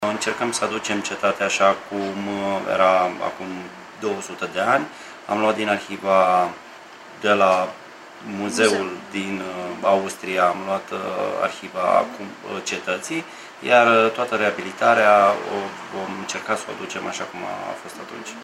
Primarul Făgăraşului, Gheorghe Sucaciu: